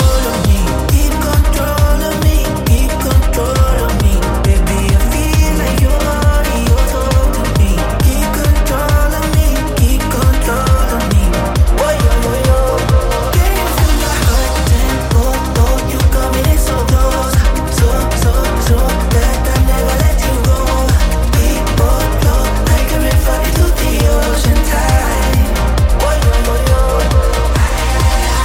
Genere: pop,dance,afrobeat,house.remixhit